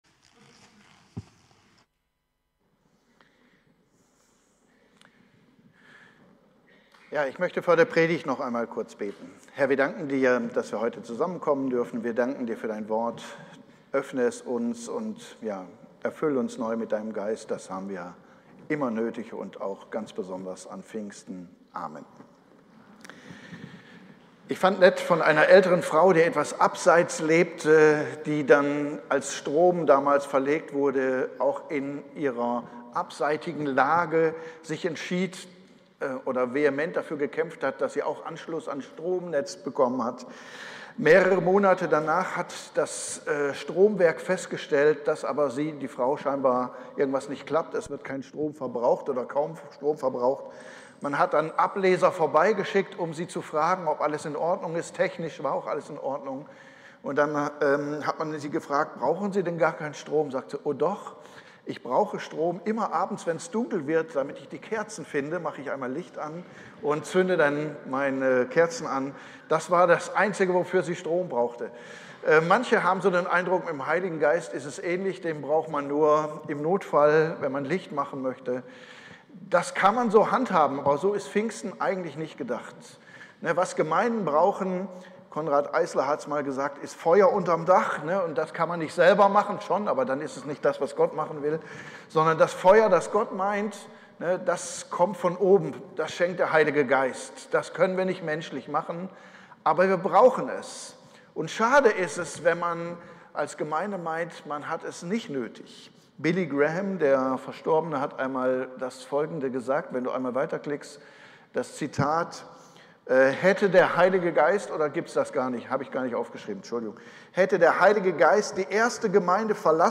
Pfingsten